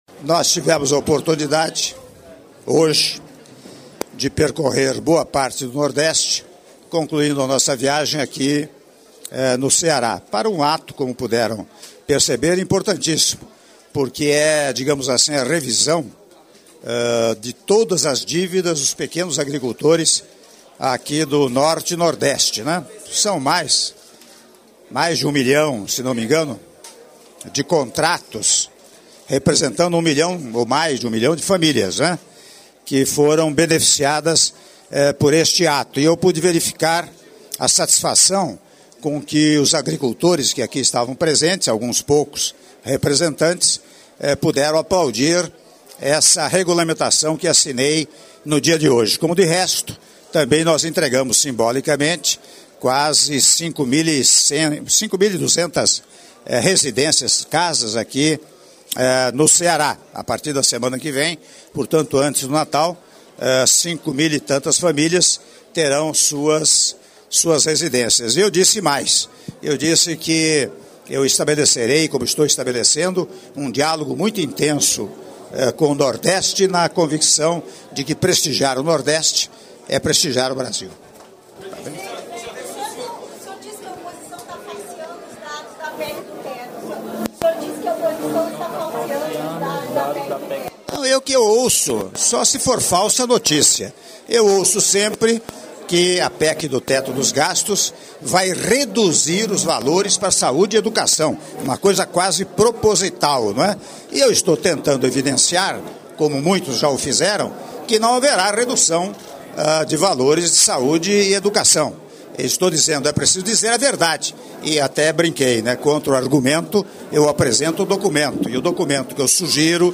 Áudio da entrevista coletiva concedida pelo presidente da República, Michel Temer, após cerimônia de Assinatura de Atos - Fortaleza/CE (3min17s)